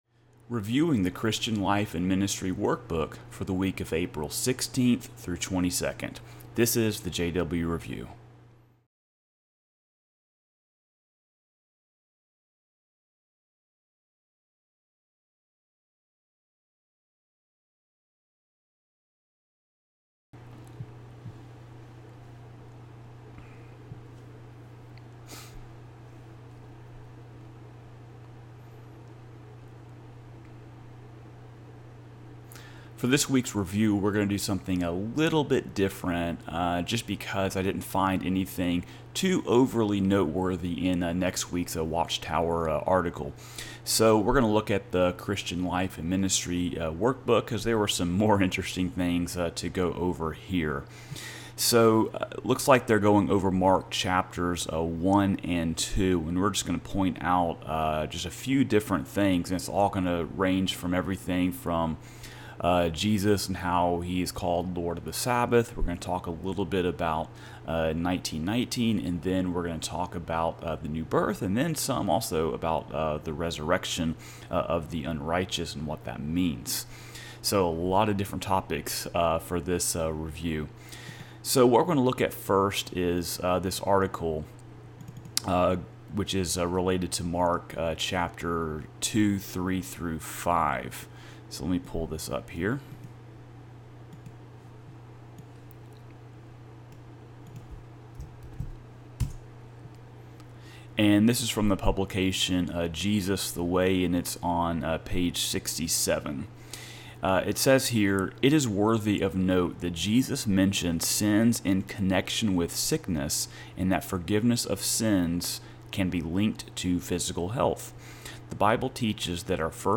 I apologize for the blank spaces in the video/audio. I had a power outage during my editing, which shut everything down and compromised the file.